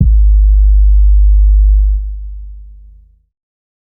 lex trunk 808.wav